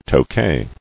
[To·kay]